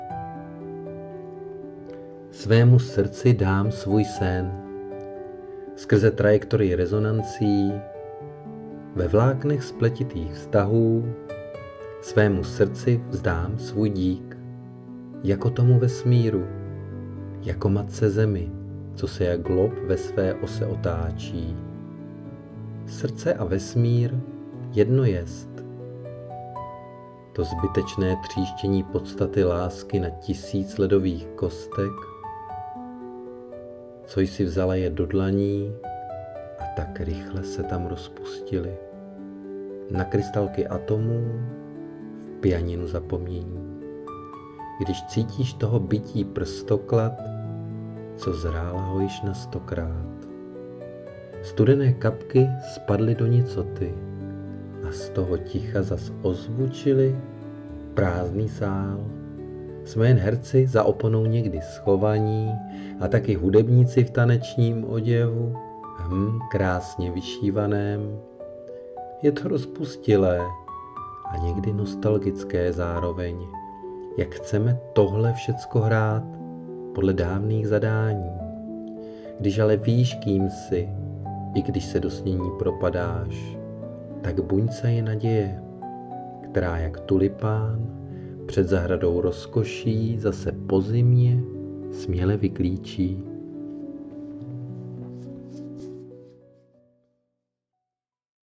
Pěkně komponované s hudbou*